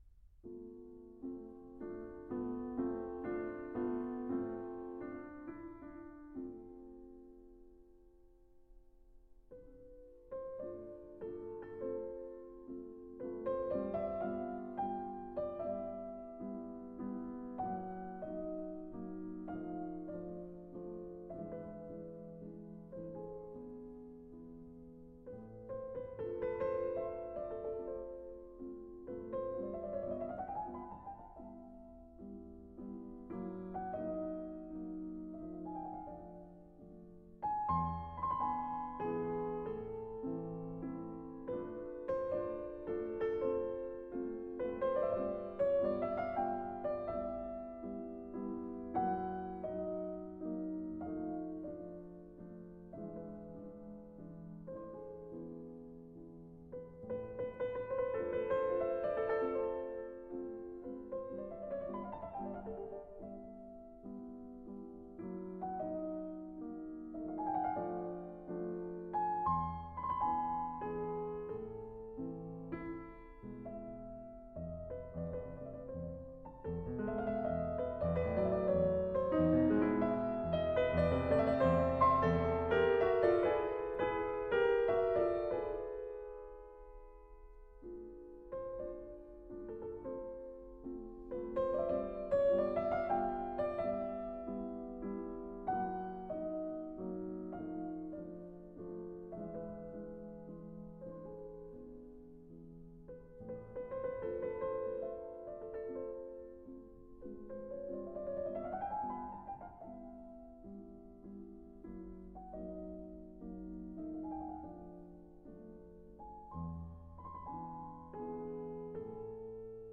09.Mazurka in a-moll